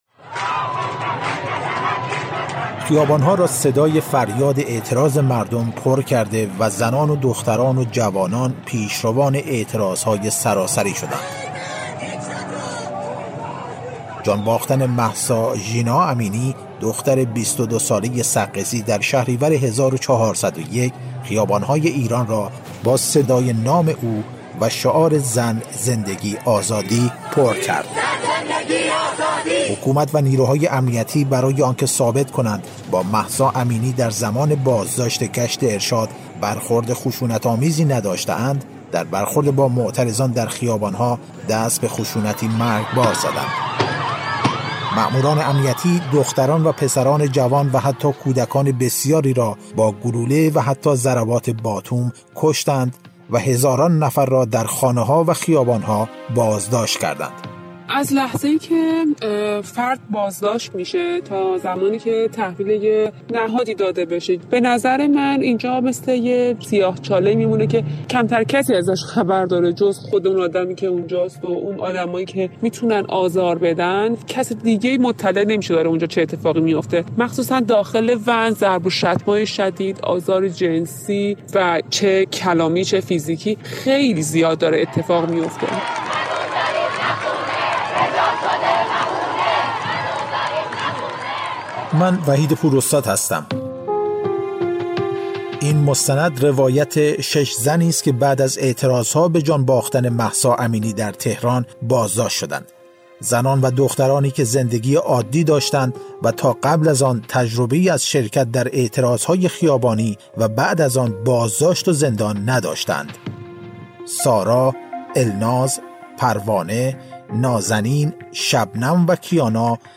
مستند رادیویی